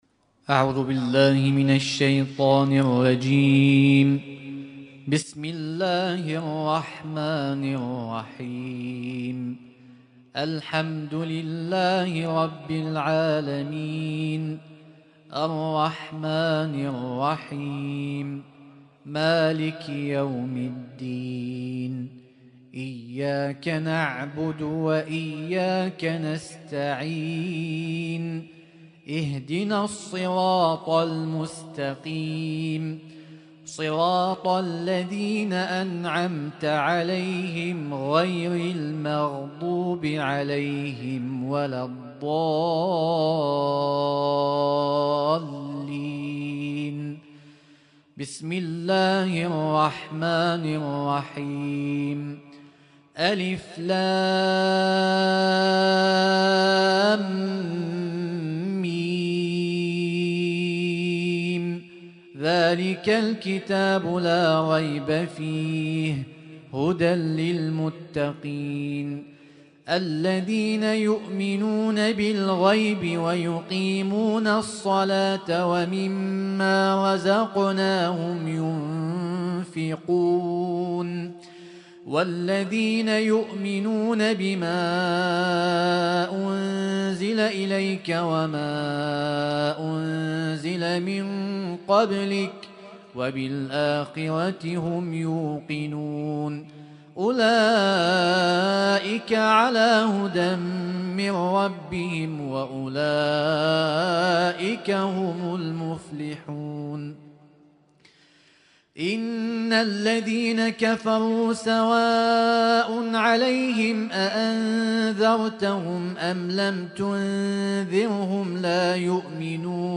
Husainyt Alnoor Rumaithiya Kuwait
ليلة (1) من شهر رمضان المبارك 1446هـ